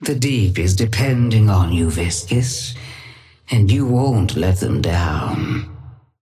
Sapphire Flame voice line - The Deep is depending on you, Viscous, and you won't let them down.
Patron_female_ally_viscous_start_01.mp3